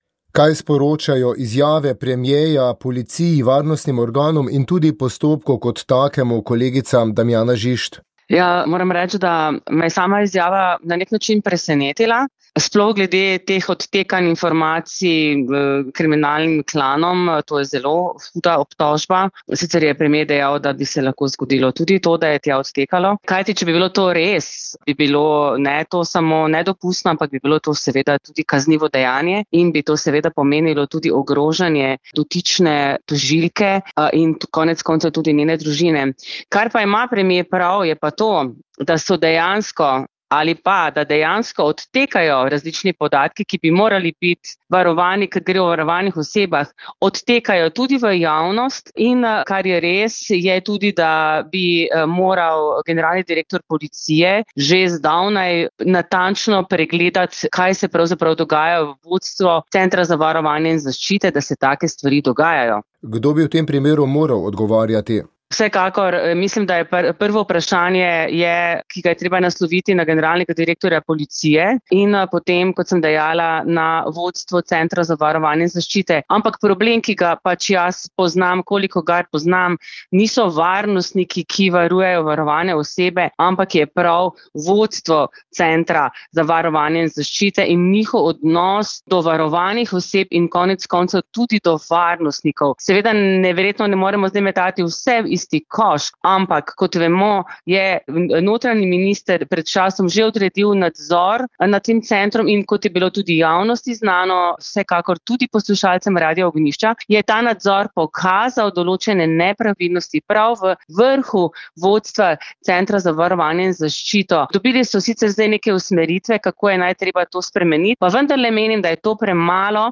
Za komentar izvolitve Slovenije v Varnostni svet smo prosili tudi nekdanjega predstavnika naše države v svetovni organizaciji Ernesta Petriča.